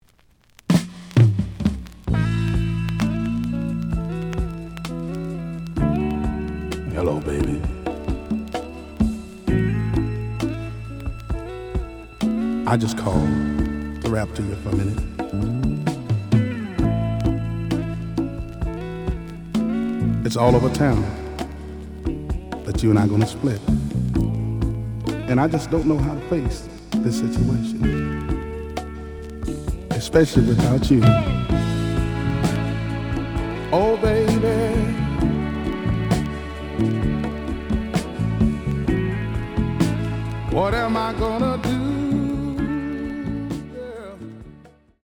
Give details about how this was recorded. The audio sample is recorded from the actual item. Slight damage on both side labels. Plays good.)